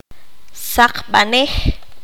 [ʔa hep’ naχ wi.’naχ maʂ maʛ.’kam noʔ ɓa.’lam] frase hombres mataron el jaguar